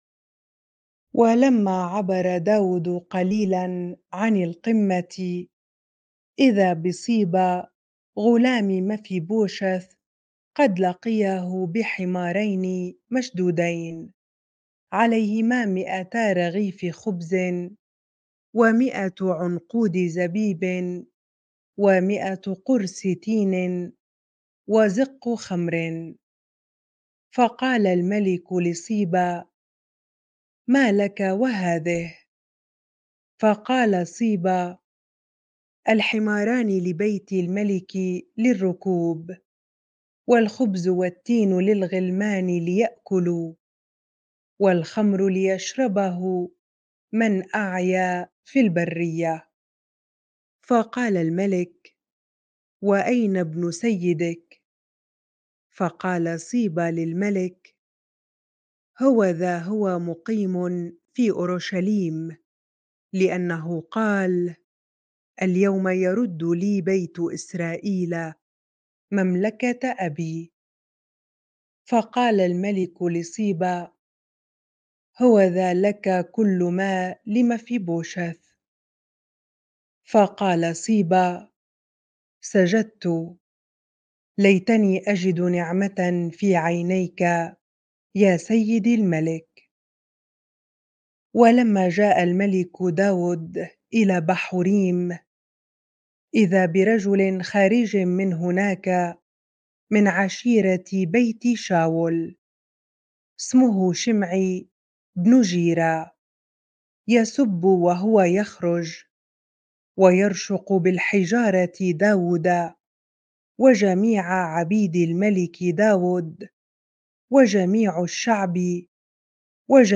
bible-reading-2Samuel 16 ar